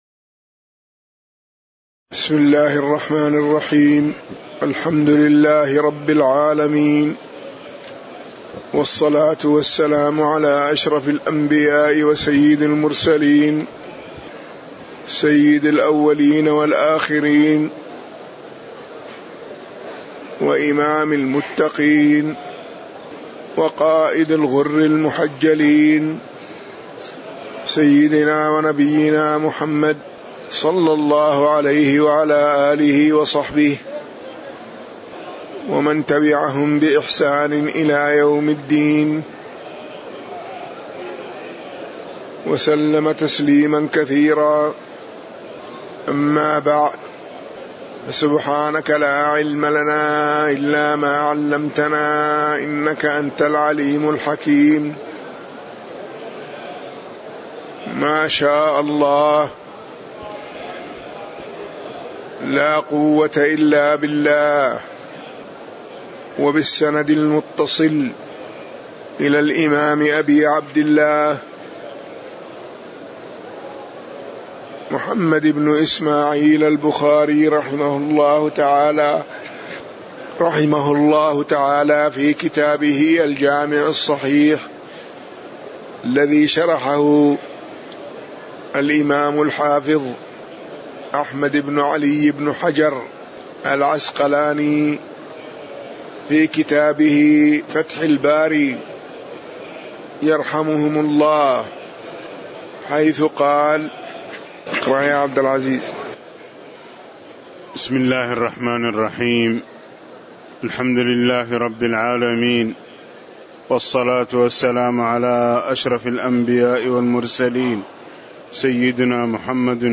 تاريخ النشر ١٠ ذو القعدة ١٤٣٨ هـ المكان: المسجد النبوي الشيخ